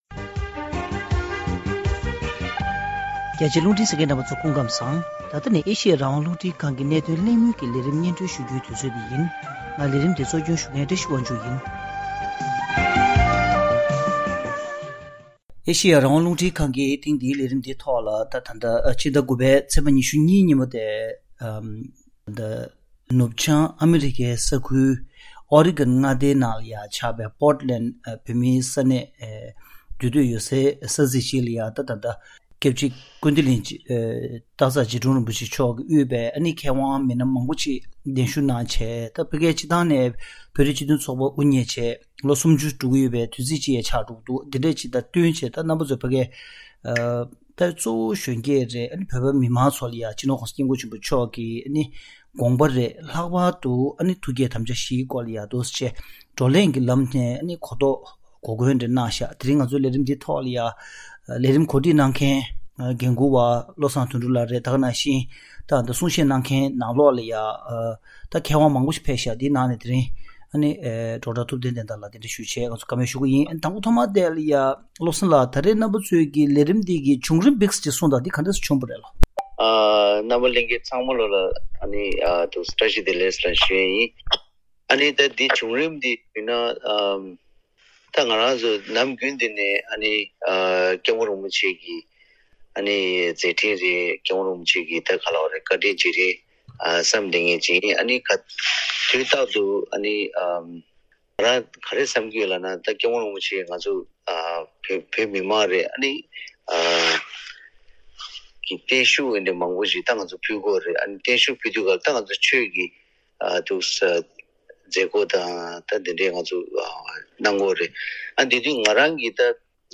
གླེང་མོལ་ཞུས་པའི་ལས་རིམ།